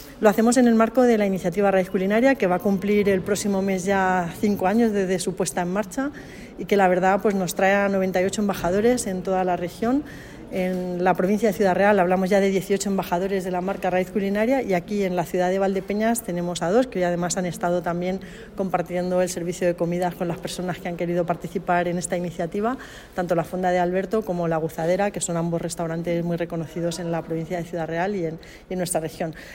>> Patricia Franco clausura la jornada Culinaria Sala Pro en Valdepeñas
patricia_franco_embajadores_valdepenas.mp3